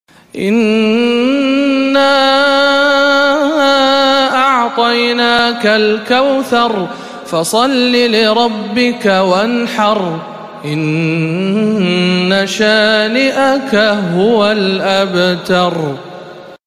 سورة الكوثر - تلاوات رمضان 1437 هـ